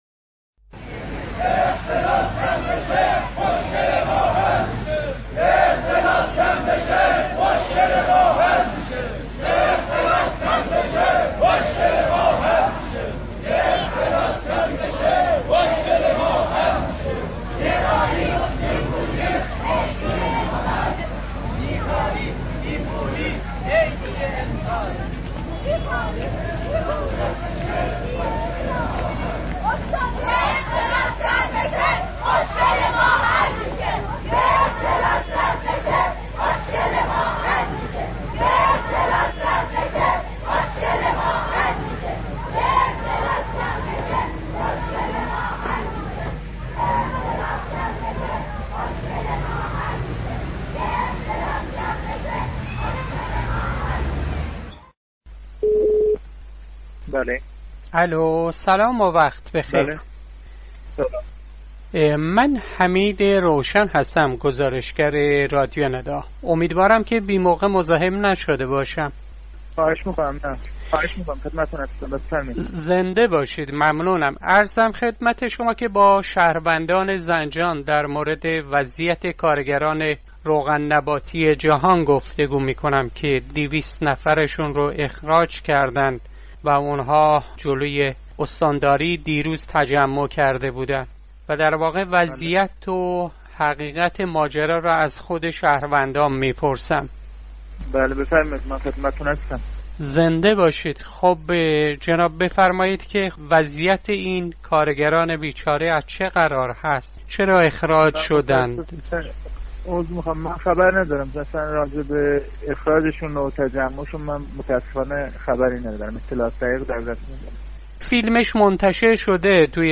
گفتگوي راديو ندا با يكي از شهروندان در زنجان پيرامون اعتراض كارگران اخراجي '' روغن نباتي جهان '' با شعار : يك اختلاس كم بشه مشكل ما حل ميشه!